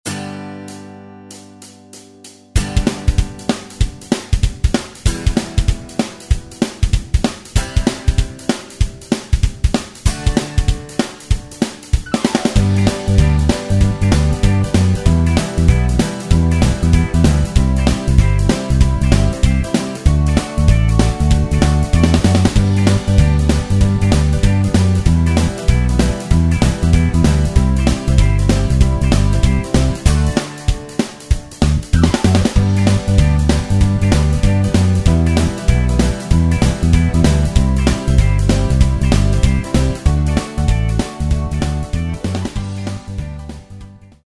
sans choeurs avec clic de départ